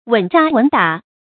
注音：ㄨㄣˇ ㄓㄚ ㄨㄣˇ ㄉㄚˇ
穩扎穩打的讀法